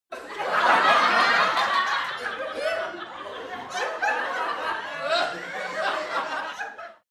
laughing 2